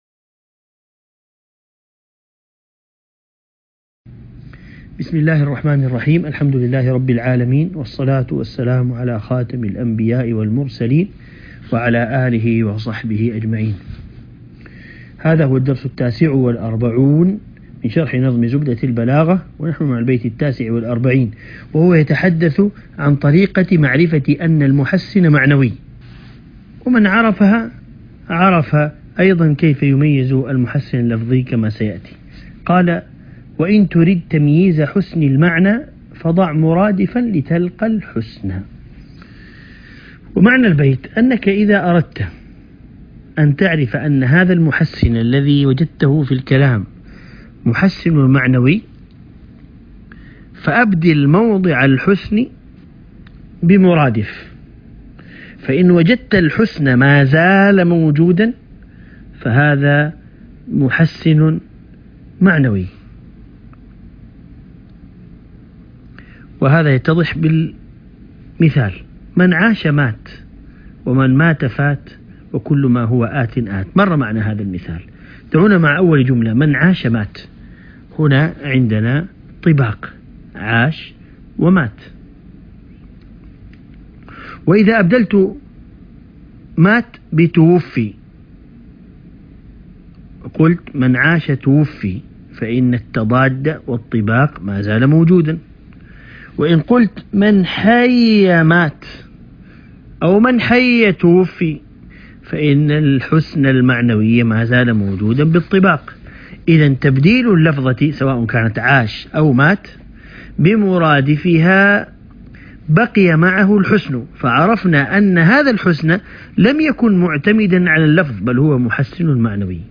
عنوان المادة الدرس ( 49) شرح نظم زبدة البلاغة